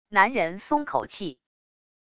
男人松口气wav音频